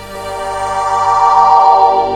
STACKPAD  -R.wav